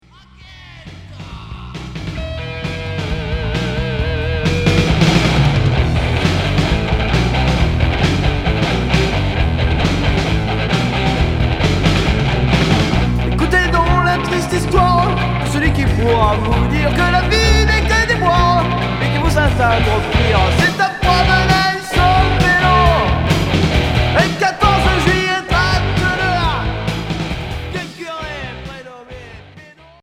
Rock hard